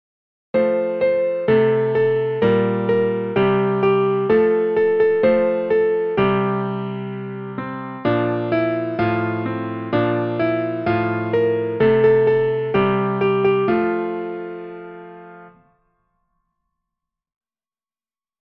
traditional Nursery Rhyme for children
for piano